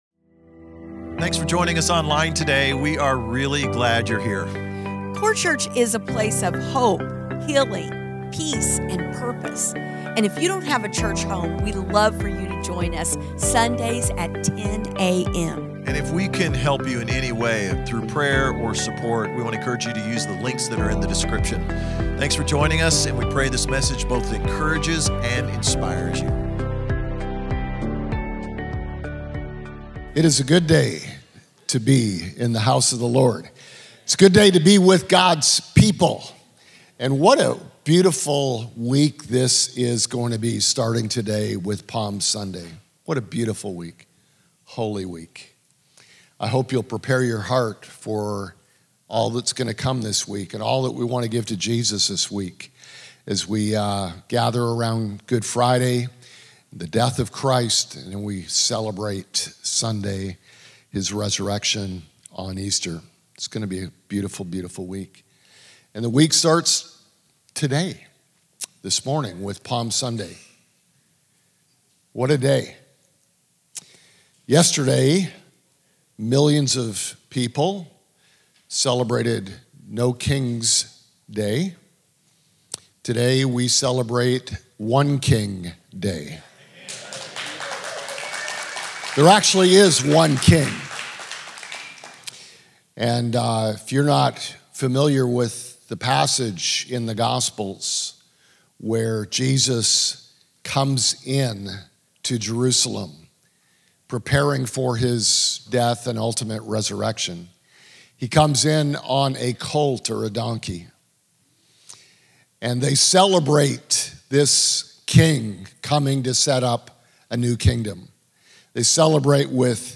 Palm Sunday 2026